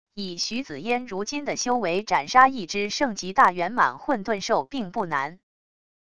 以许紫烟如今的修为斩杀一只圣级大圆满混沌兽并不难wav音频生成系统WAV Audio Player